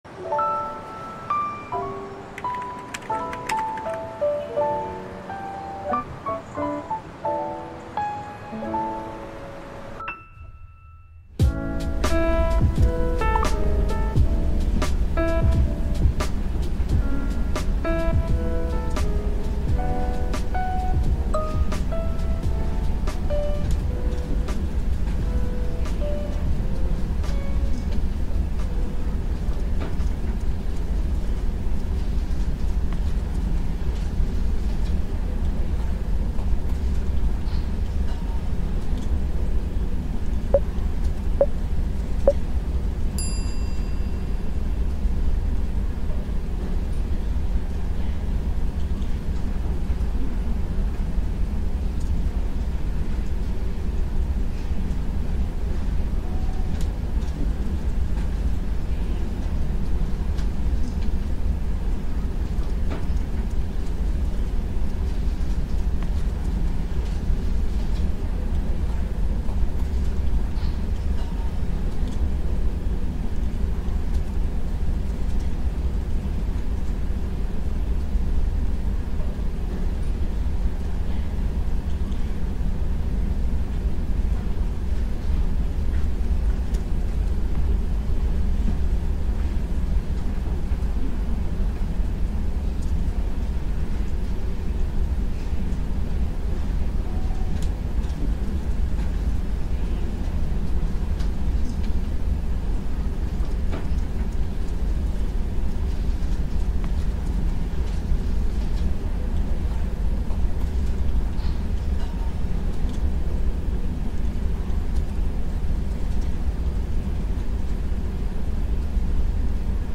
☕ Café Session IRL — 1-Hour Real Study Ambience Without Loops